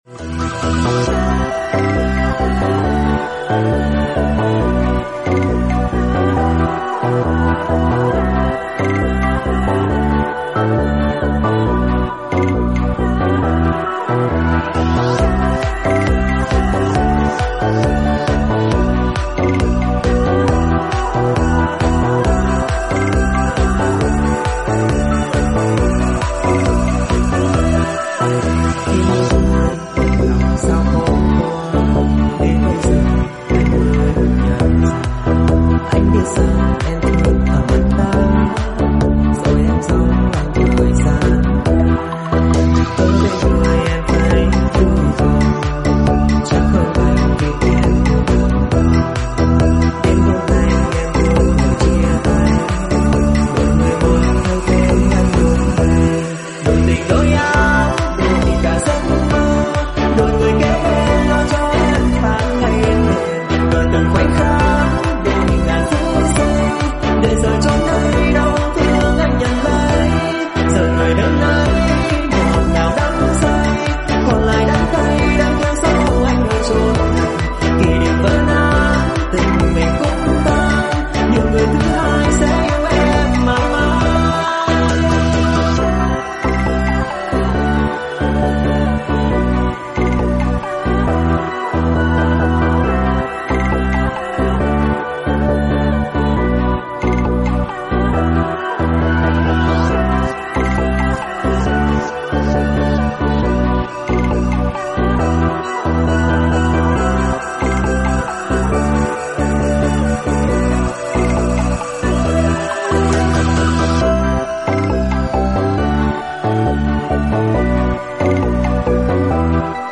#8dmusic